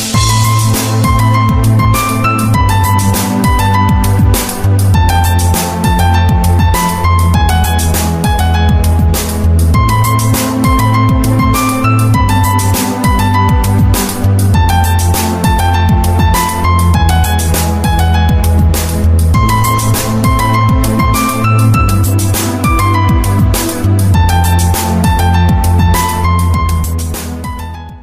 Música Clasica
NUEVO Y HERMOSO TONO DE Música DE INSPIRACIÓN CLÁSICA